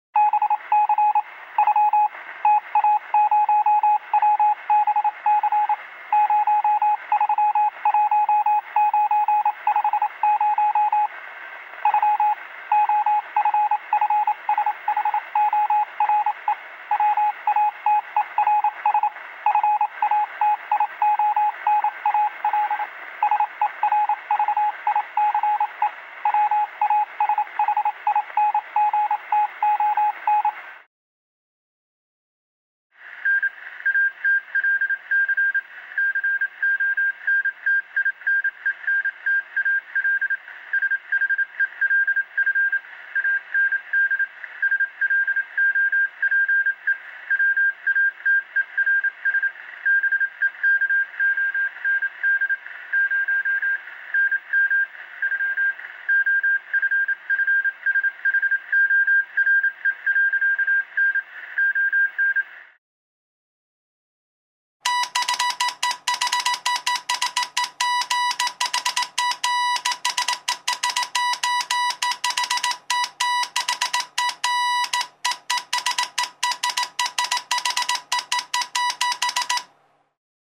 Звуки радио, помех
Азбука Морзе: прием и передача сигналов